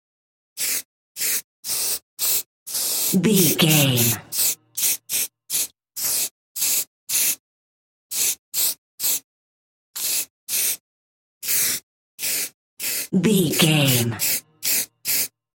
Graffiti aerosol spray short
Sound Effects
foley